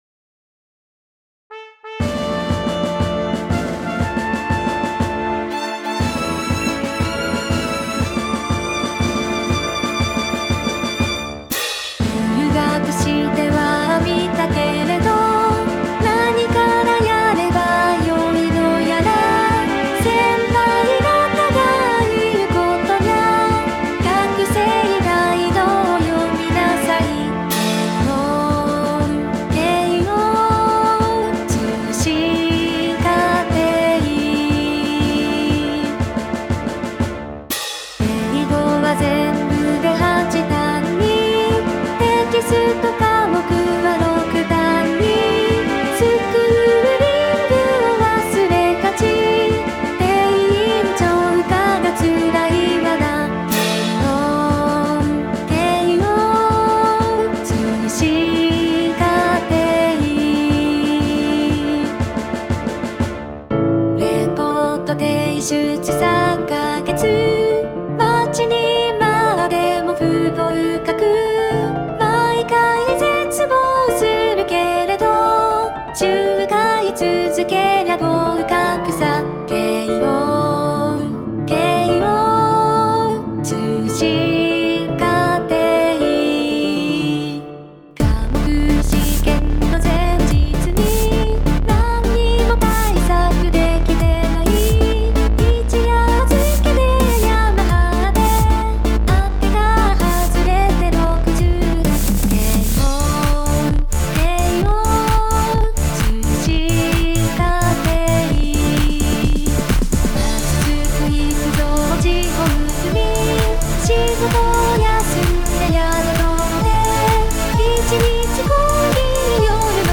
毎回慶應～慶應～通信課程～とラストに入れて何番も積み重ねる感じにしていきたいです。
歌にはNEUTRINOというボカロ的なものを使っています。